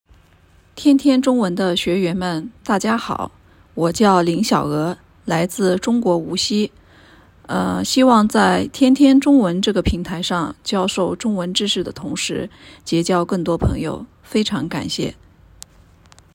少しクールな印象を受けるものの、はきはきとお話をされる何事も真面目に取り組まれる方です。